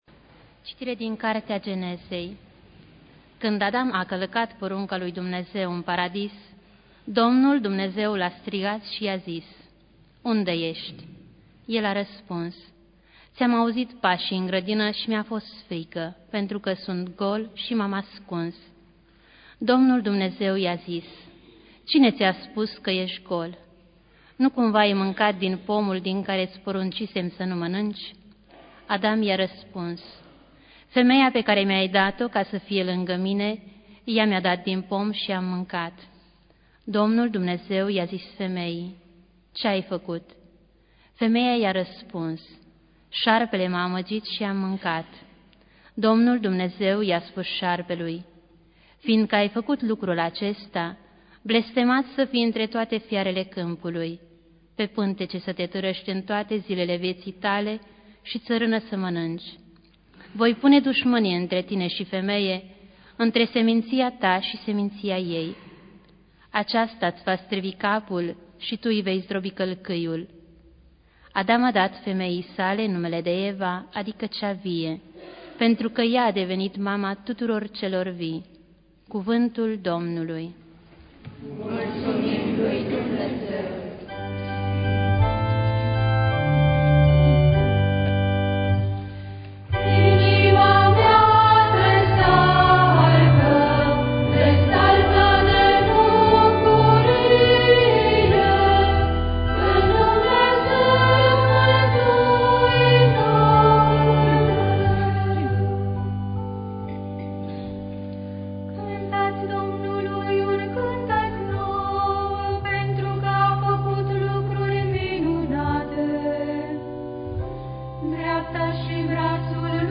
Biblioteca - Predici la Radio Iasi